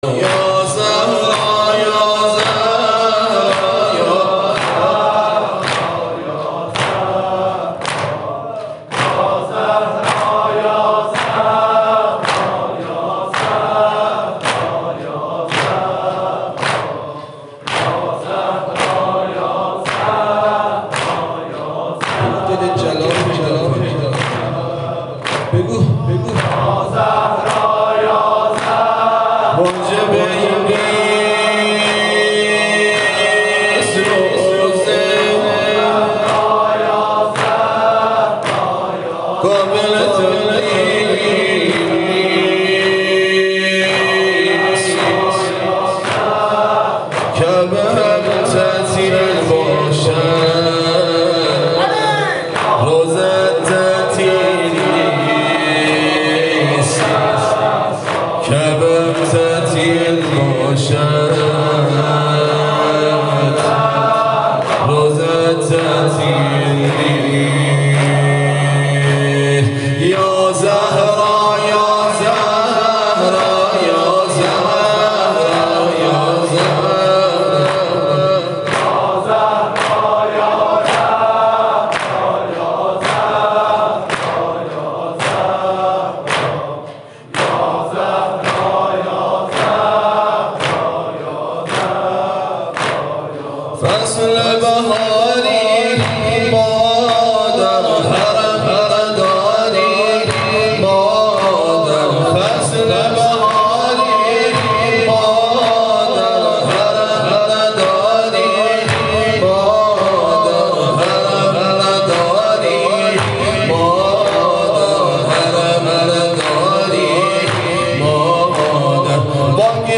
سالگرد شهادت حاج قاسم سلیمانی